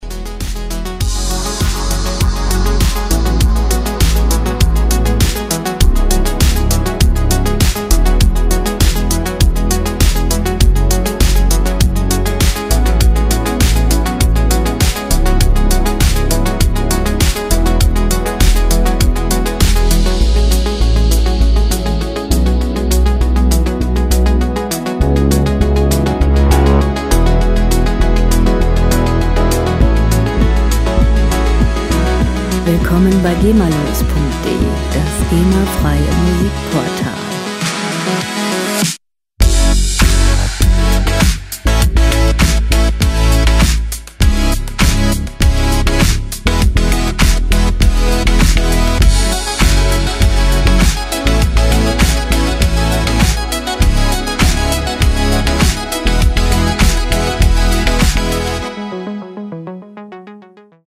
Elektronische Musik - Moderne Welt
Musikstil: Electro Pop
Tempo: 100 bpm
Tonart: Fis-Moll
Charakter: lebendig, kraftvoll
Instrumentierung: Synthesizer, digitaler Drumcomputer